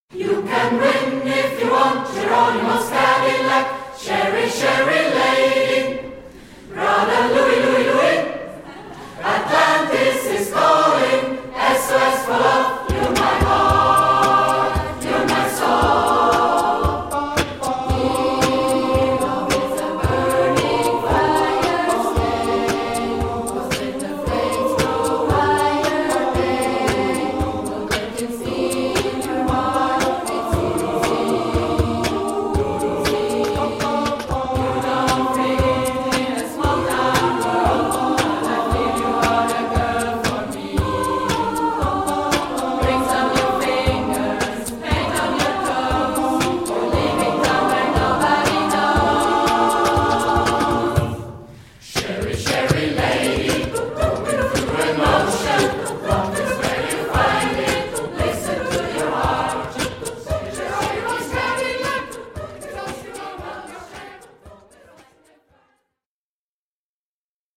Medley mit